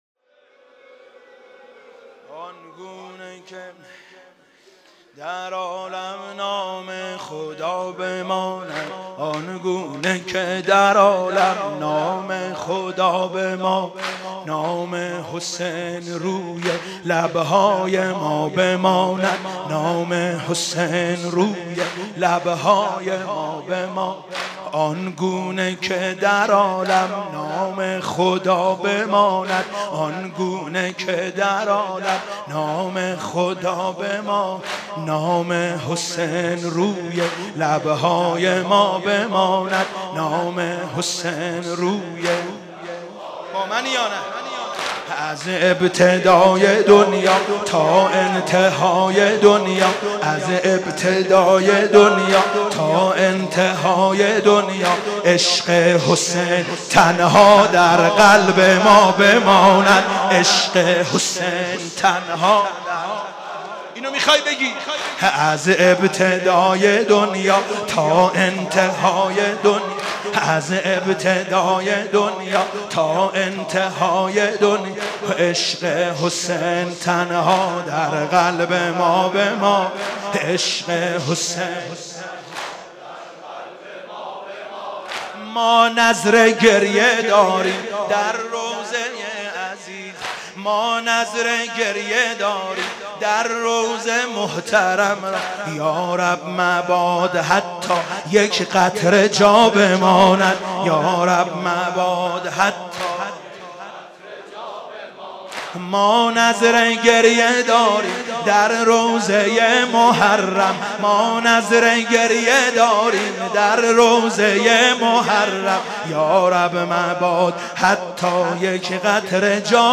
شب سوم محرم97 - مسجد امیر - سنگین - آنگونه که در عالم نام